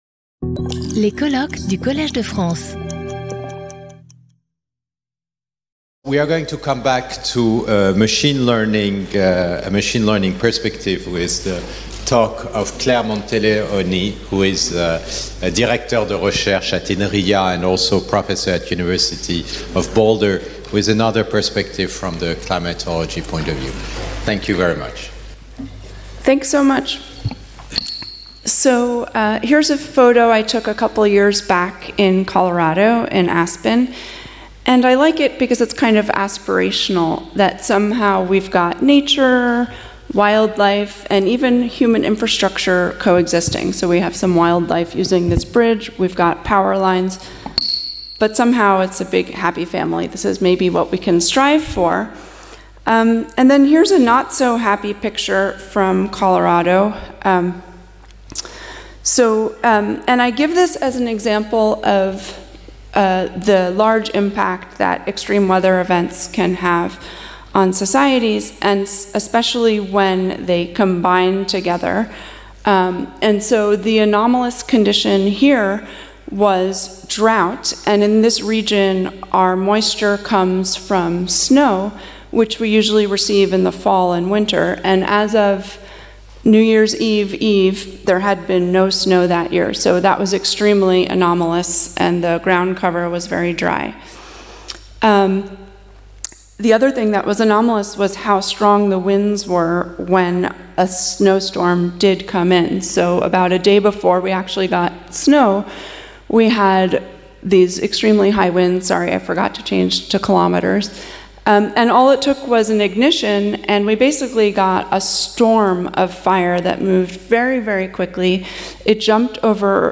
In this lecture, I will survey our lab's work developing generative and self-supervised machine learning approaches for applications addressing climate change, including downscaling and temporal interpolation of spatiotemporal data and generating probabilistic weather predictions.